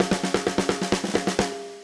AM_MiliSnareA_130-02.wav